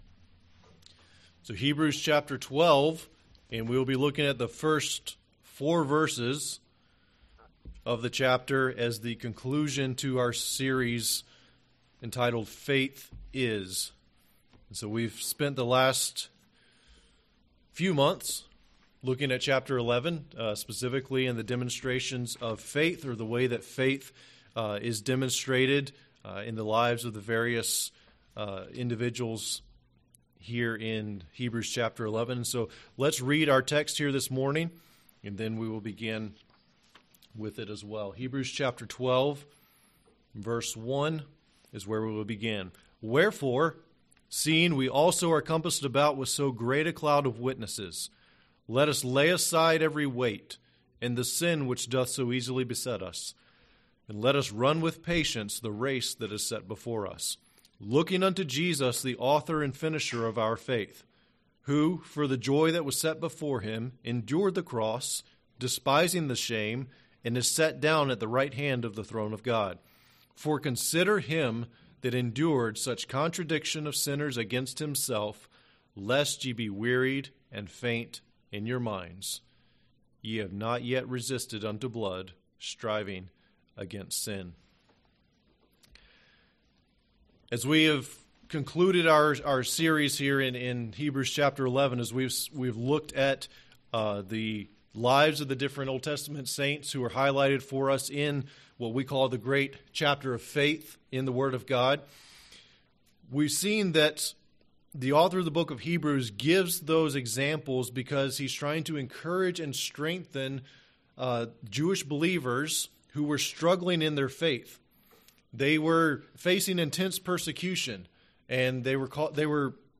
In this concluding message from the Faith Is... series, we consider Jesus and the help that we receive for living by faith when we keep our focus on Him.